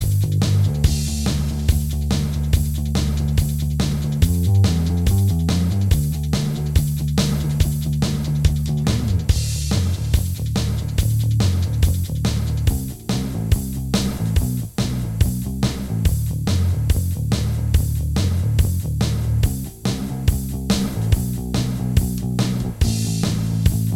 Minus Guitars Rock 4:17 Buy £1.50